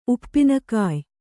♪ uppina kāy